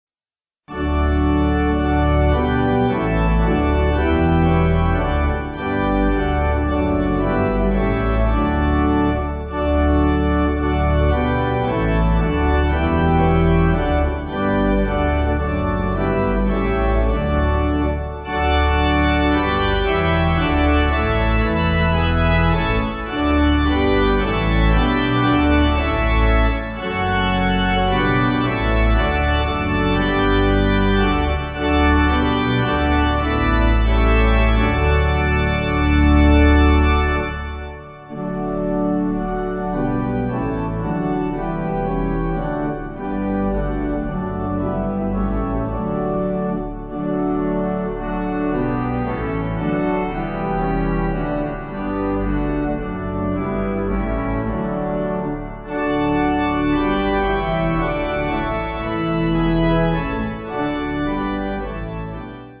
Alternative timing